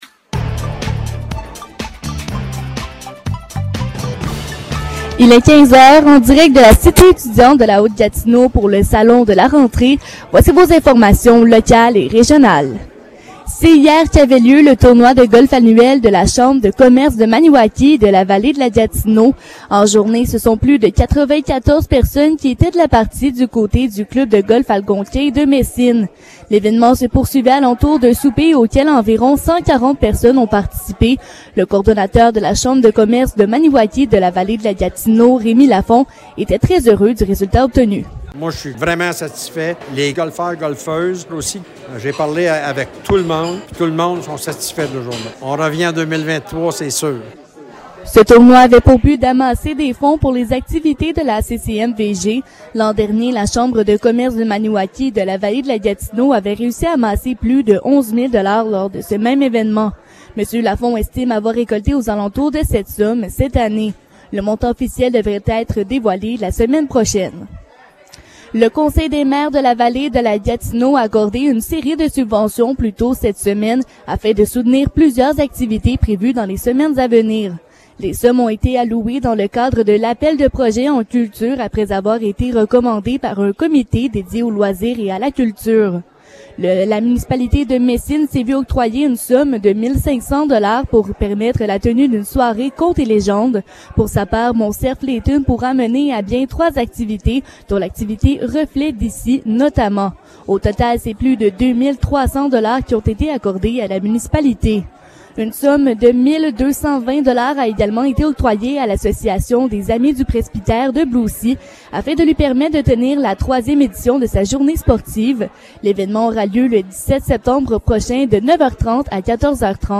Nouvelles locales - 25 août 2022 - 15 h